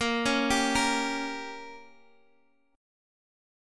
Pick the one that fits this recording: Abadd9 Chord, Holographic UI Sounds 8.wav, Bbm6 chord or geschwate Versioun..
Bbm6 chord